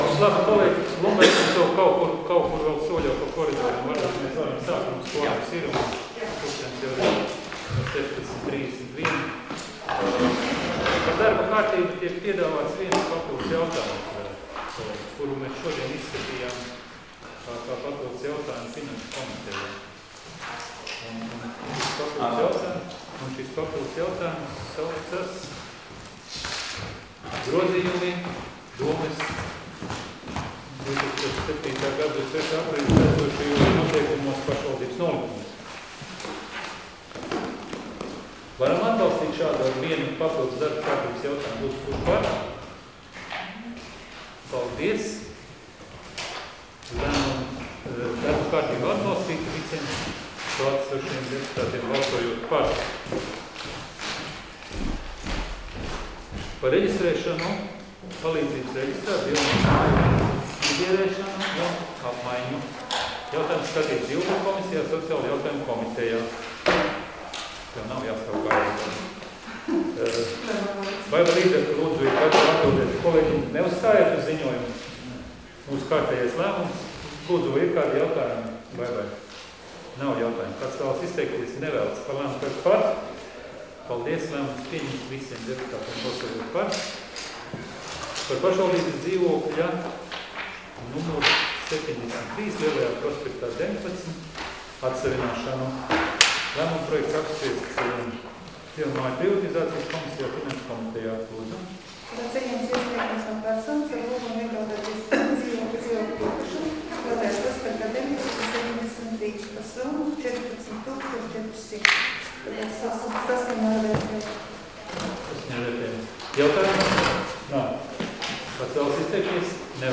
Domes sēdes 02.09.2021. audioieraksts